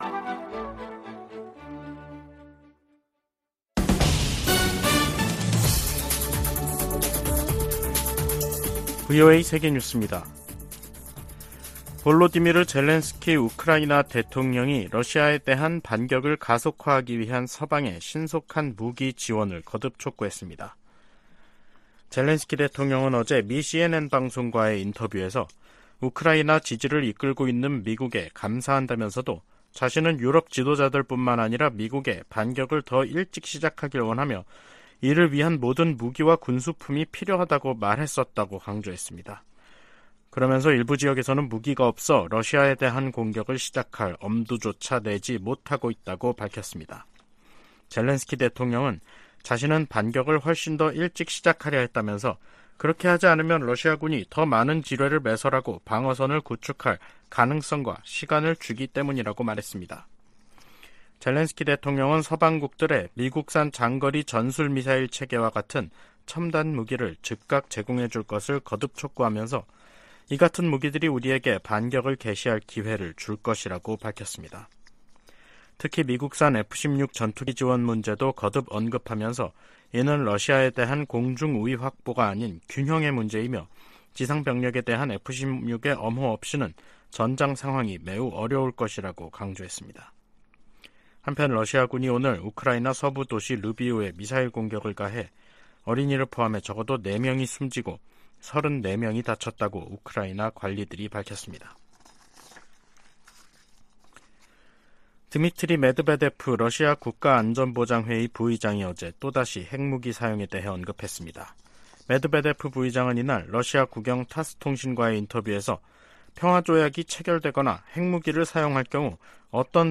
세계 뉴스와 함께 미국의 모든 것을 소개하는 '생방송 여기는 워싱턴입니다', 2023년 7월 6일 저녁 방송입니다. '지구촌 오늘'에서는 러시아 용병 업체 '바그너 그룹'의 예브게니 프리고진 창립자가 러시아로 돌아갔다고 알렉산드르 루카셴코 벨라루스 대통령이 밝힌 소식 전해드리고, '아메리카 나우'에서는 국방부가 기밀 통제를 강화하는 이야기 살펴보겠습니다.